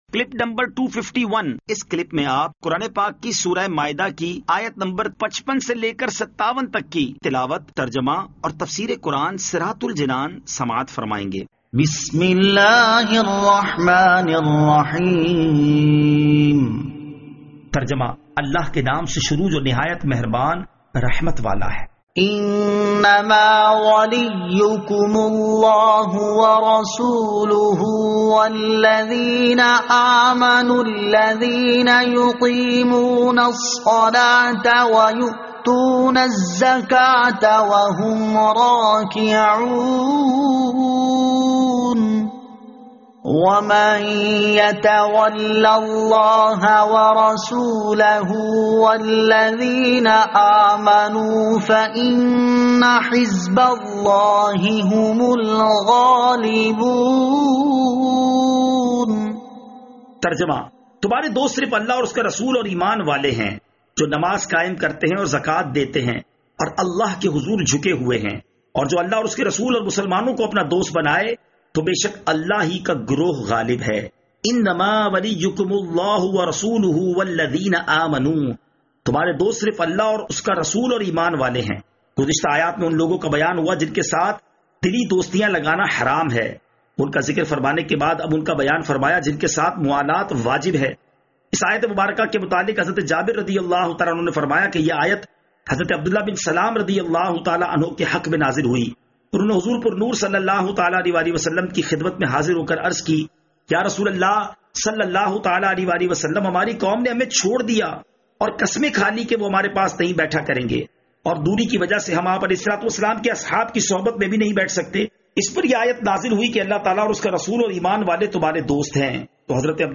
Surah Al-Maidah Ayat 55 To 57 Tilawat , Tarjama , Tafseer